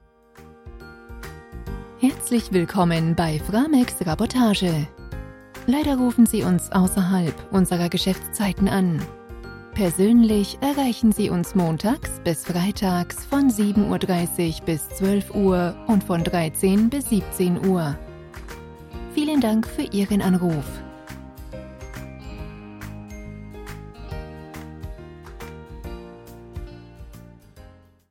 Message d'Accueil Téléphonique
Exemples de messages vocaux:
Nos acteurs voix off réalisent pour vous le message vocal parfait!